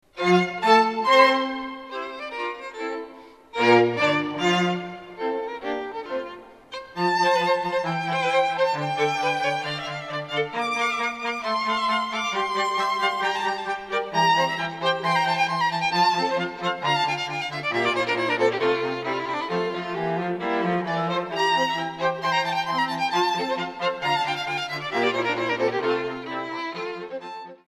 Festliche Streicherklänge zur Zeremonie
A Streichquartett (unsere Grundbesetzung: 2 Violinen, 1 Viola, 1 Violoncello)
(Besetzung A: Streichquartett)